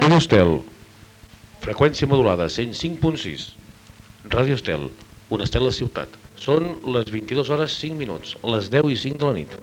Identificació de l'emissora i hora.